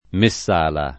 Messala [ me SS# la ] → Messalla